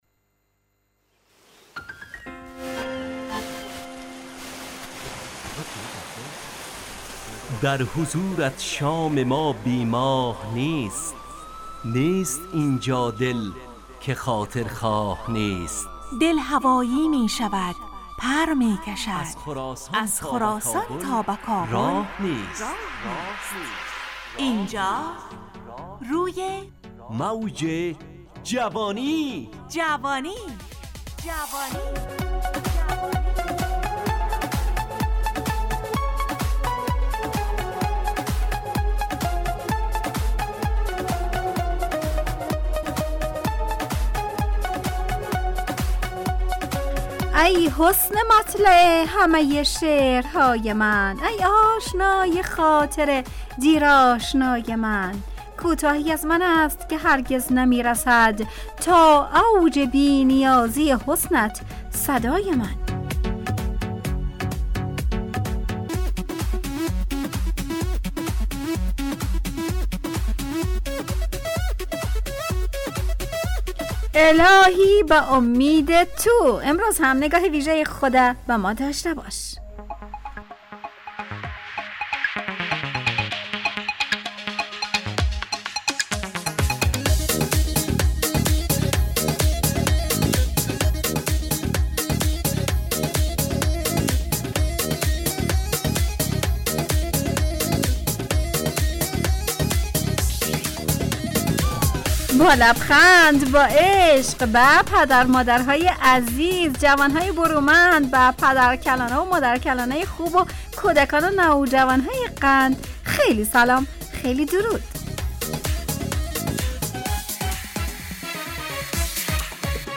روی موج جوانی، برنامه شادو عصرانه رادیودری.
همراه با ترانه و موسیقی مدت برنامه 70 دقیقه .